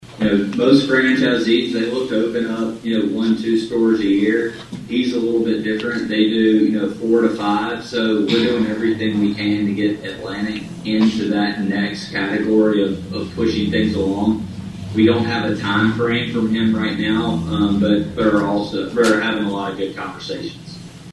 attended the Atlantic City Council meeting via Zoom on Wednesday.